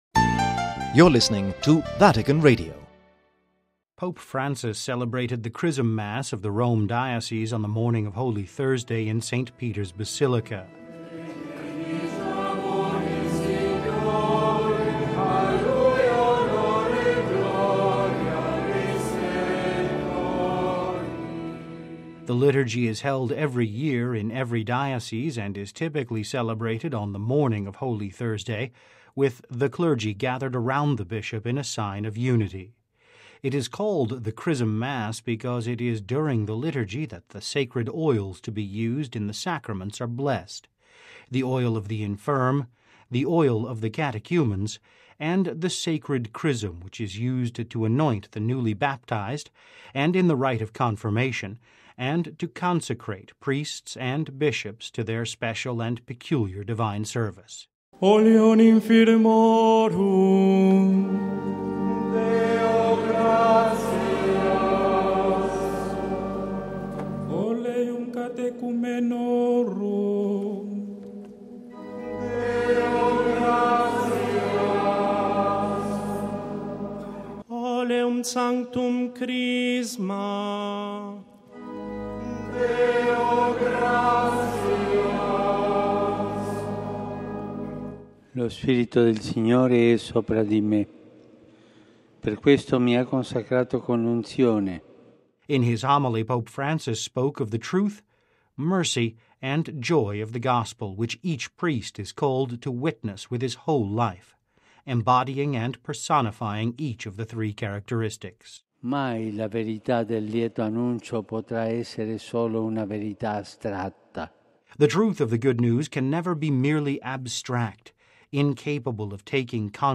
(Vatican Radio) Pope Francis celebrated the Chrism Mass of the Rome diocese on the morning of Holy Thursday in St. Peter’s Basilica.